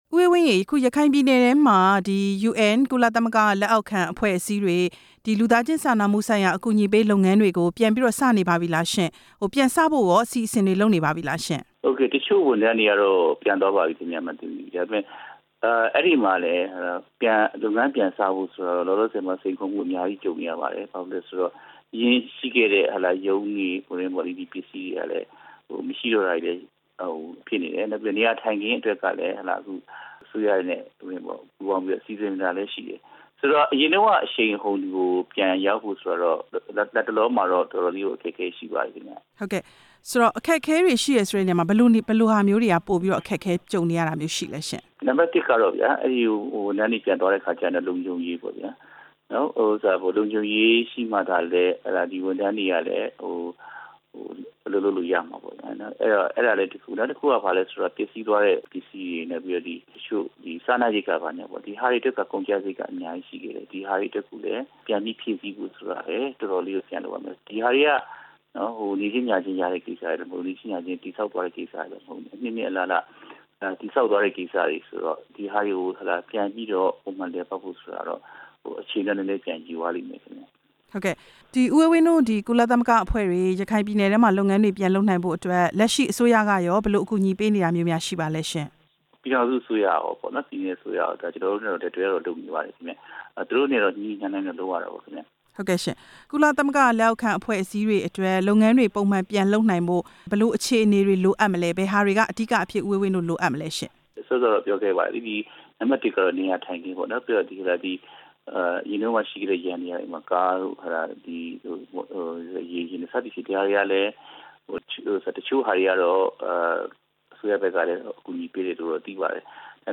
ဆက်သွယ် မေးမြန်းချက်